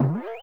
trampBounce_2.wav